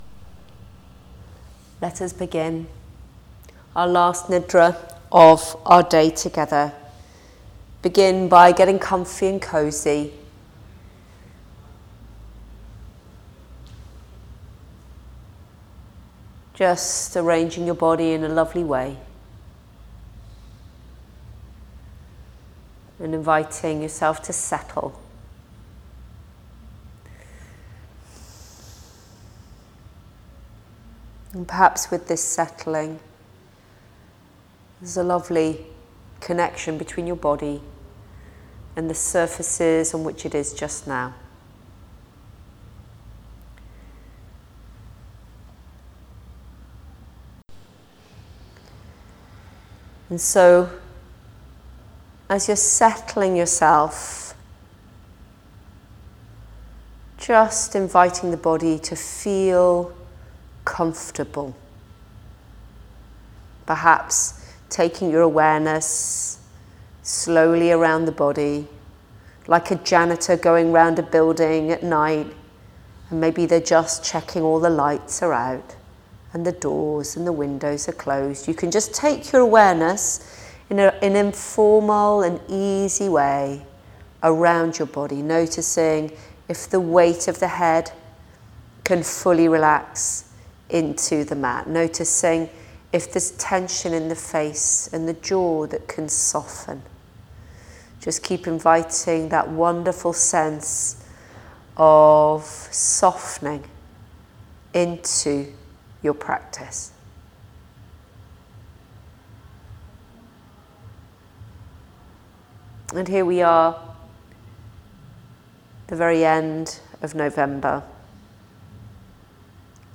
Choose from an Autumn / metal element or deeply relaxing Water element Yoga Nidra (guided Yogic Sleep)